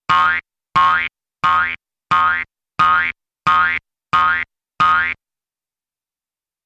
Bounce
Object Bounces ( I.e. - Pogo Stick Or Hopping ) 8x ( Jews Harp ).